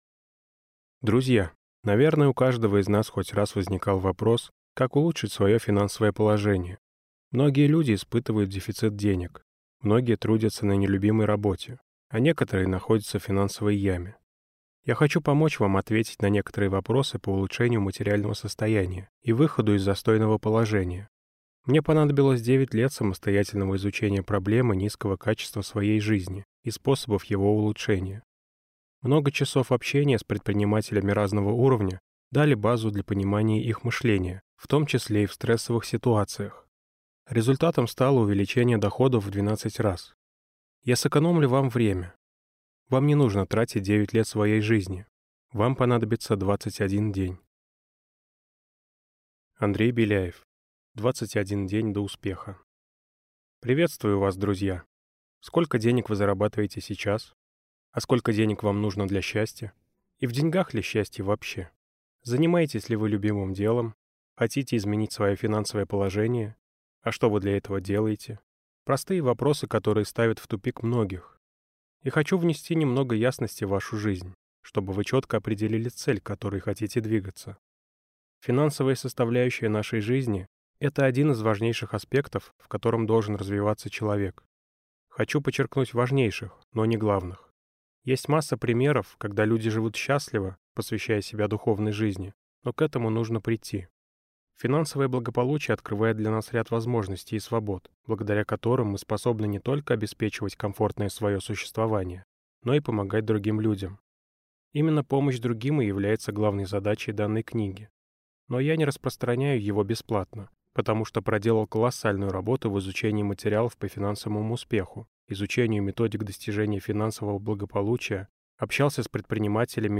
Аудиокнига 21 день до успеха | Библиотека аудиокниг
Прослушать и бесплатно скачать фрагмент аудиокниги